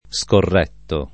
[ S korr $ tto ]